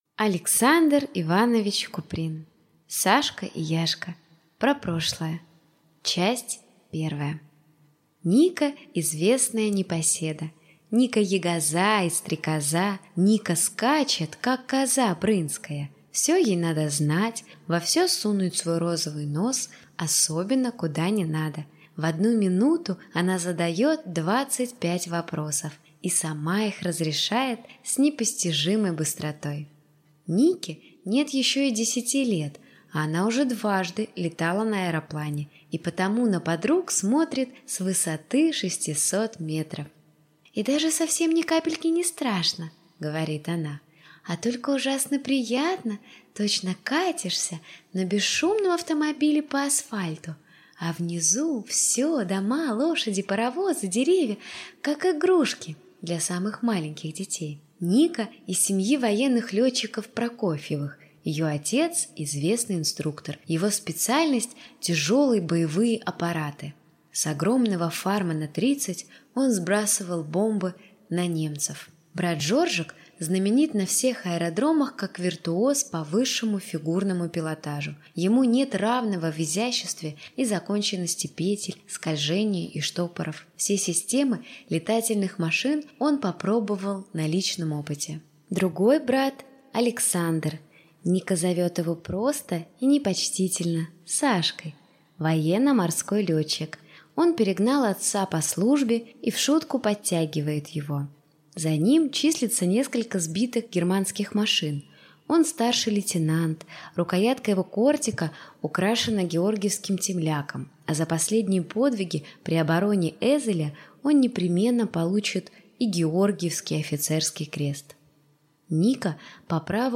Аудиокнига Сашка и Яшка | Библиотека аудиокниг